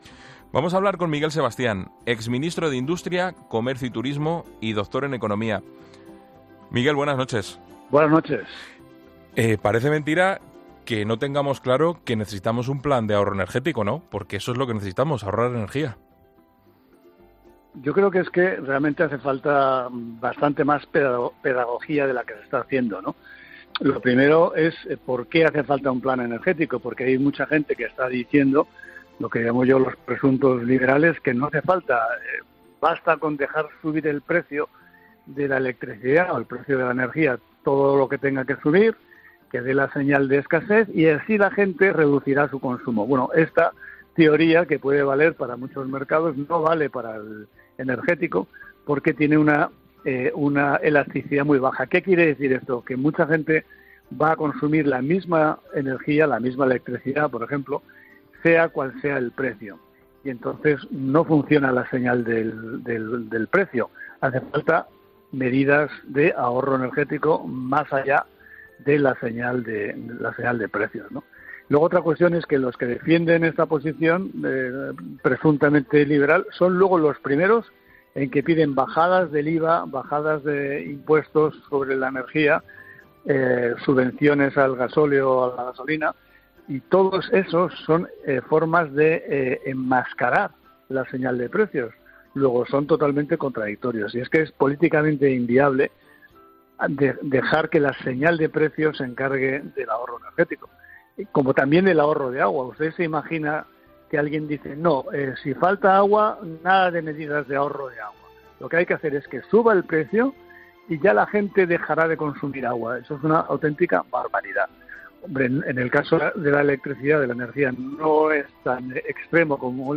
El exministro de Industria y Turismo ha pasado por los micrófonos de 'La Linterna' para analizar este plan.